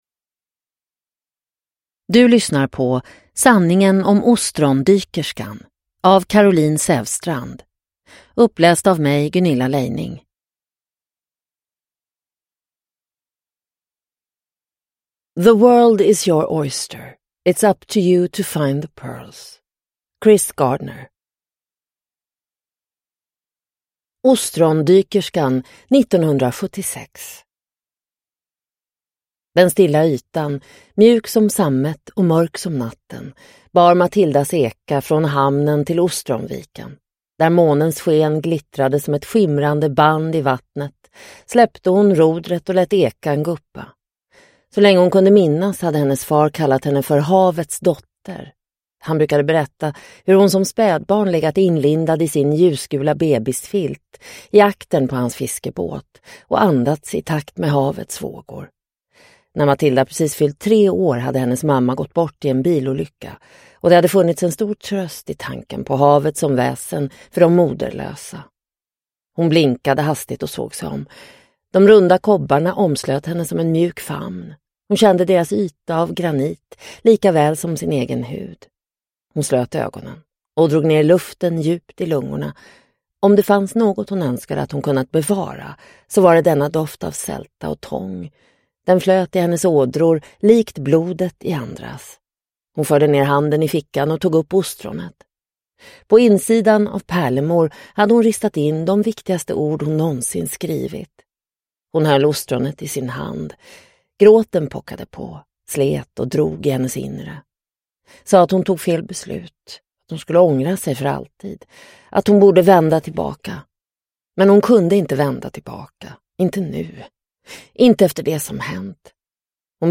Sanningen om ostrondykerskan – Ljudbok – Laddas ner